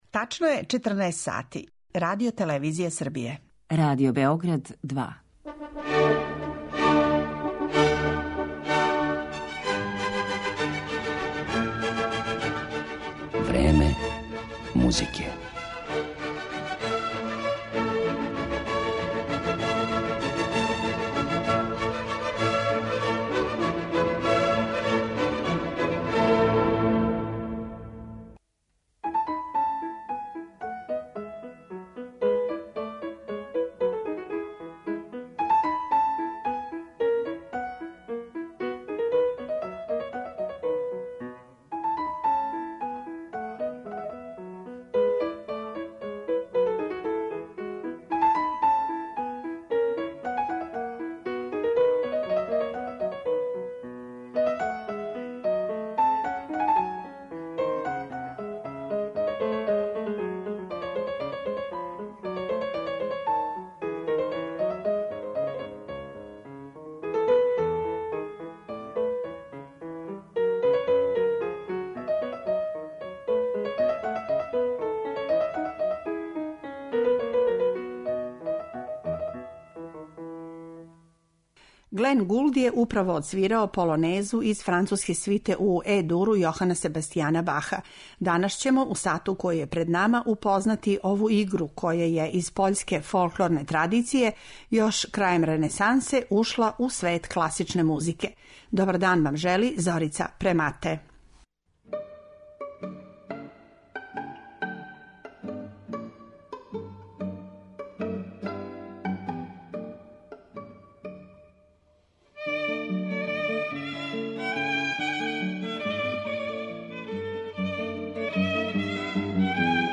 У емисији 'Време музике' слушаћете - полонезе.
Од Баха, преко Бетовена и Глинке, па до Дворжака, Чајковског и Шопена, полонезе су задржале свој свечани карактер, умерен темпо и пунктиран ритам на првој доби такта. Био је то плес пољске аристократије који је, још у 18.веку, прихватила музичка Европа.